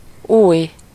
Ääntäminen
IPA: /nu.vo/